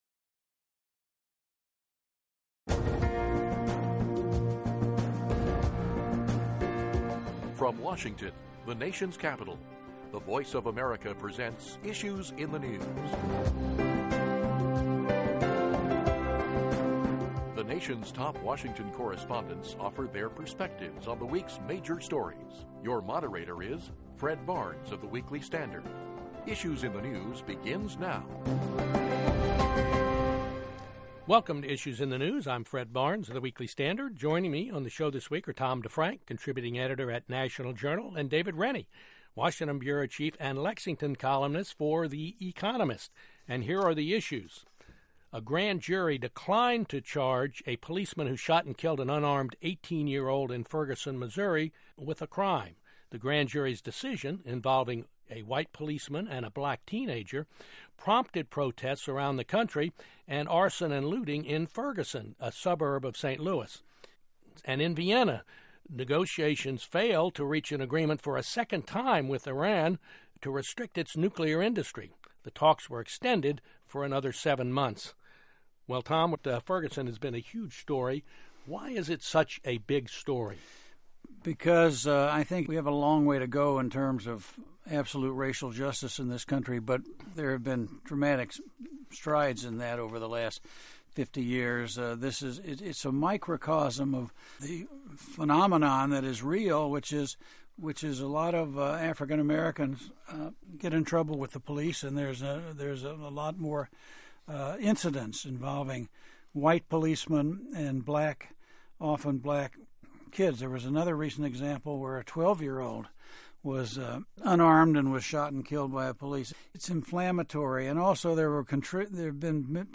Moderator Fred Barnes